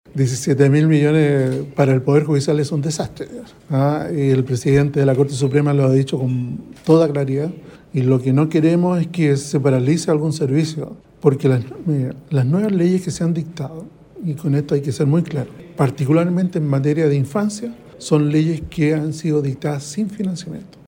De visita en el Bío Bío para lanzar la sala especializada en justicia penal juvenil del Juzgado de Garantía de Concepción, el ministro de la Corte Suprema, Diego Simpértigue, se refirió al recorte de 17 mil millones de pesos ordenado por el Ejecutivo para el presupuesto del Poder Judicial.